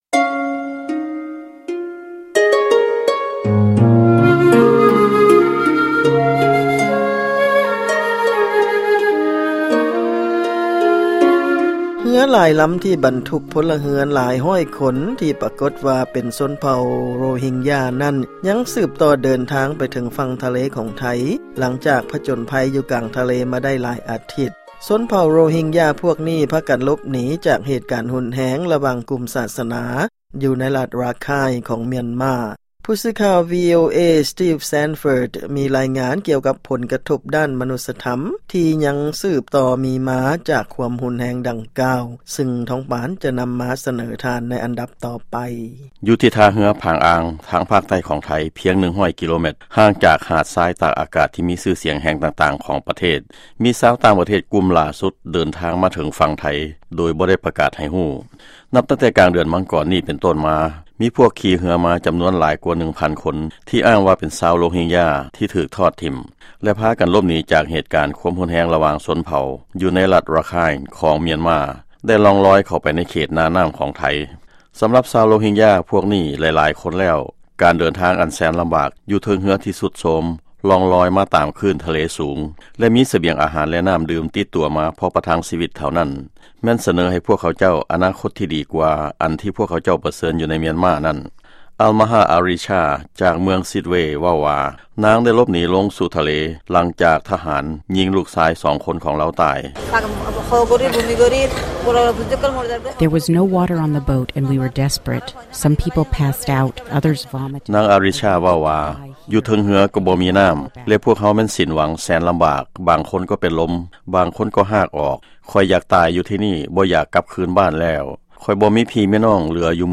ຟັງລາຍງານກ່ຽວກັບຊົນເຜົ່າໂຣຮິງຢາ